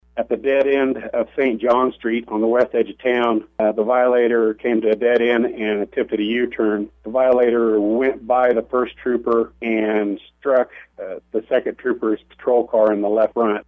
car-chase-one.mp3